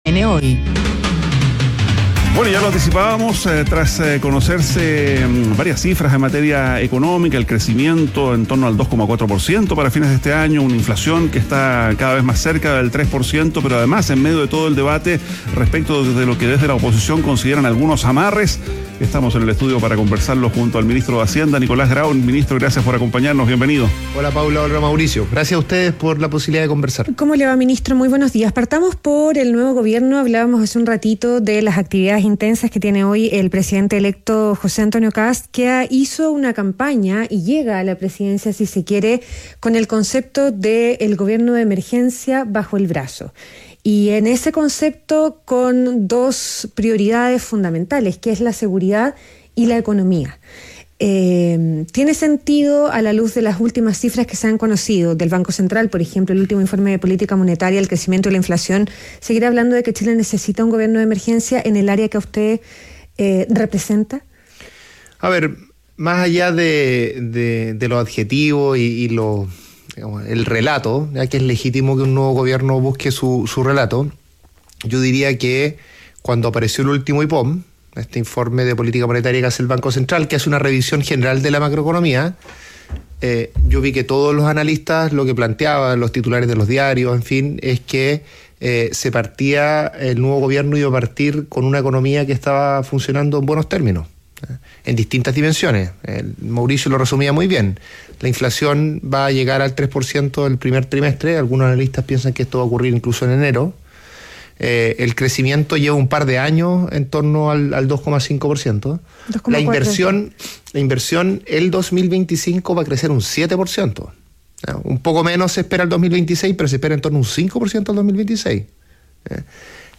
Entrevista a Nicolás Grau, ministro de Economía - ADN Hoy